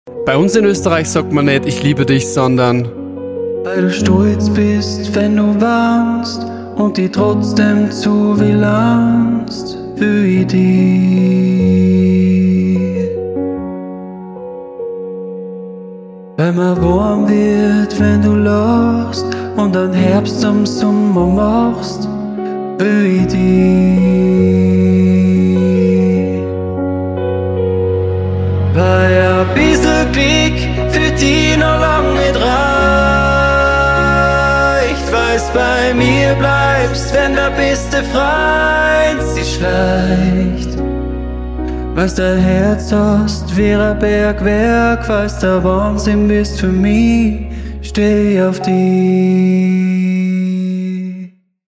Austro-Pop